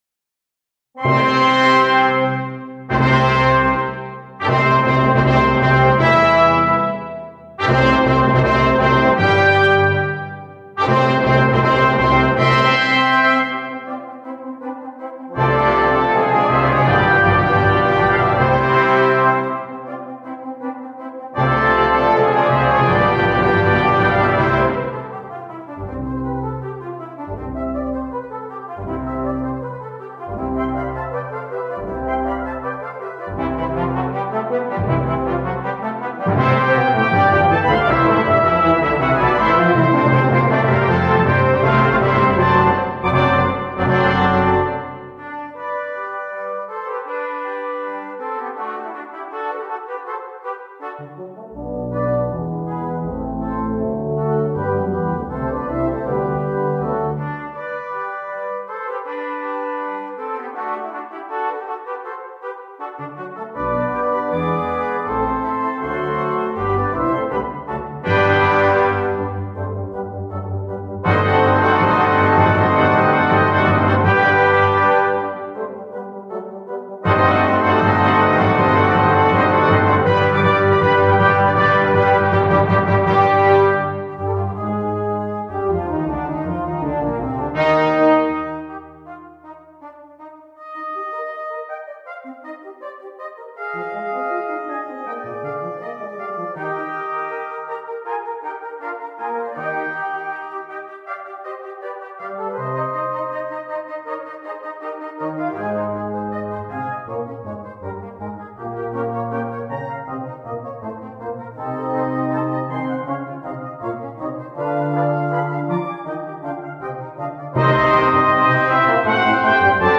Full Band
without solo instrument
Classical, Classical Overture